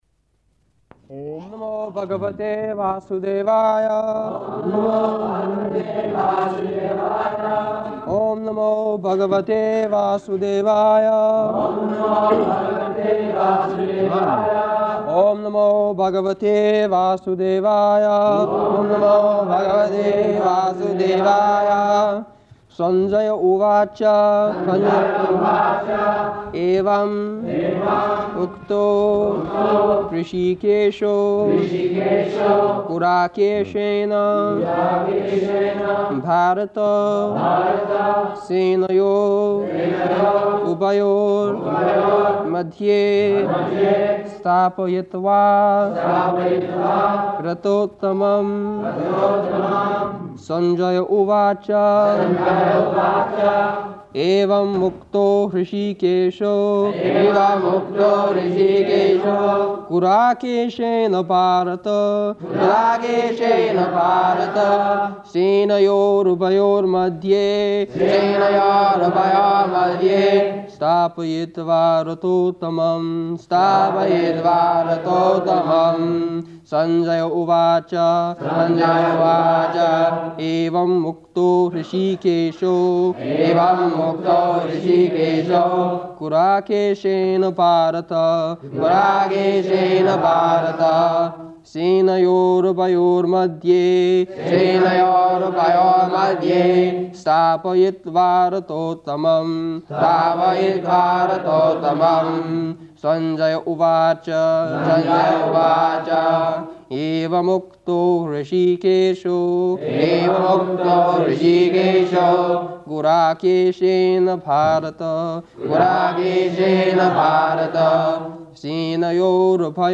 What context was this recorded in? July 20th 1973 Location: London Audio file